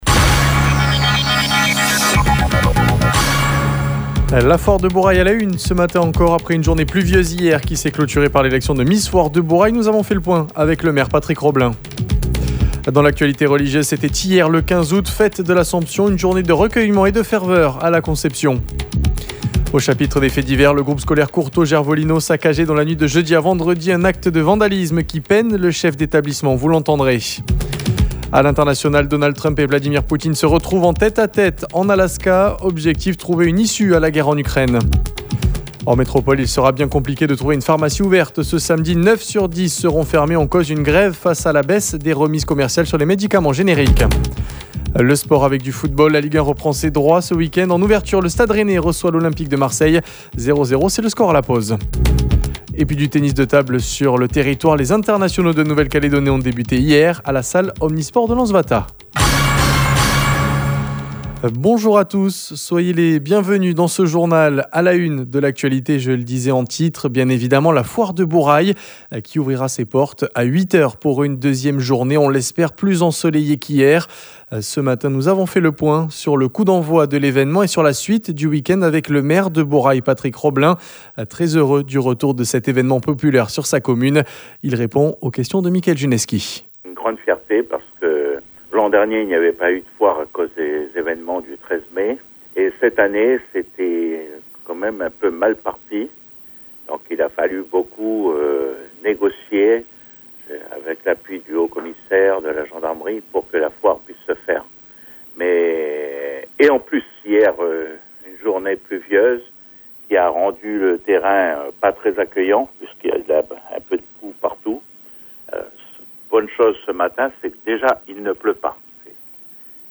Nous serons en direct de Bourail, dans notre journal, avec le maire, Patrick Robelin.